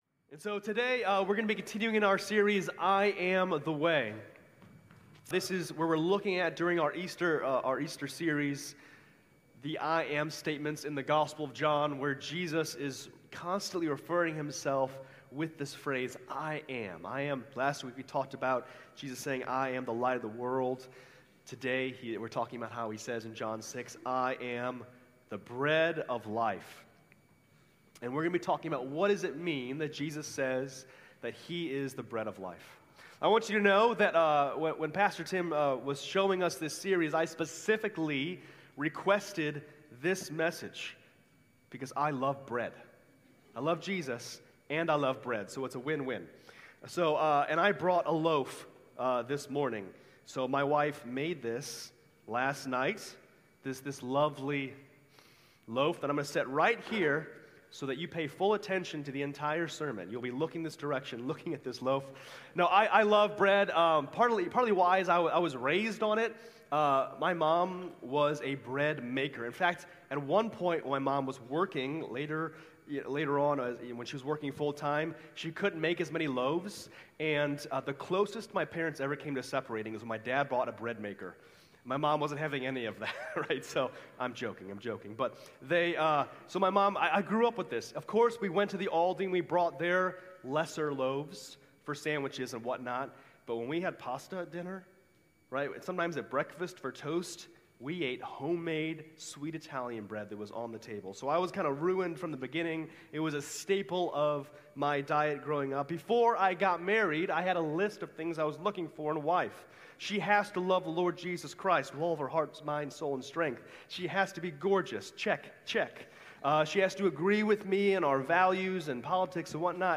March 22, 2026 Worship Service Order of Service: Welcome Community News Call to Worship Praise Songs Sermon The Lords Supper Worship Song Closing
Sermon